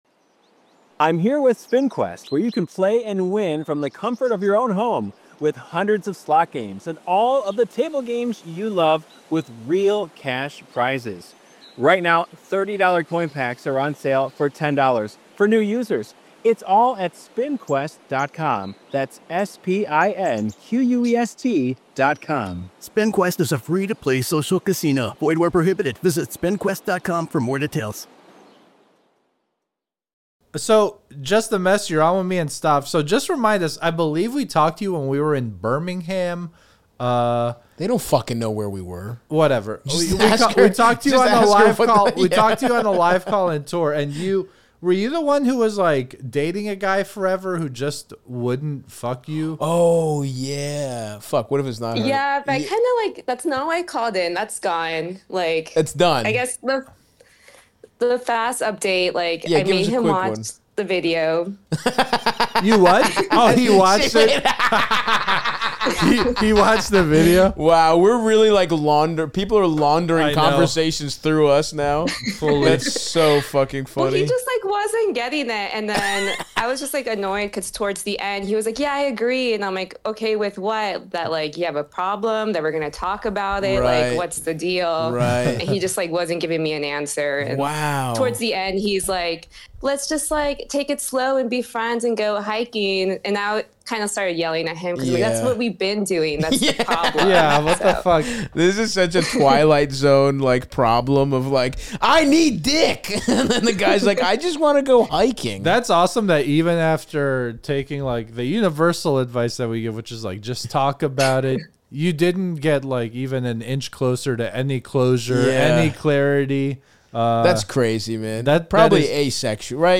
Bonus #150 - Live Call Show Vol. 33 [PATREON PREVIEW]